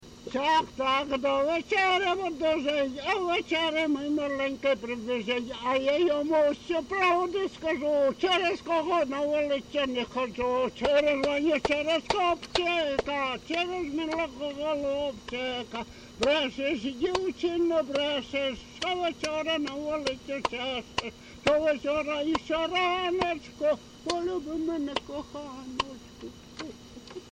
ЖанрТриндички, Жартівливі
Місце записус. Клинове, Артемівський (Бахмутський) район, Донецька обл., Україна, Слобожанщина